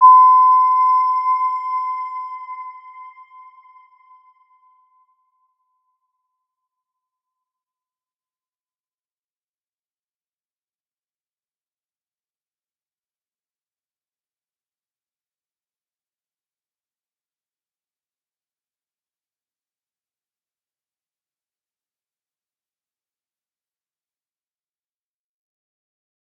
Round-Bell-C6-f.wav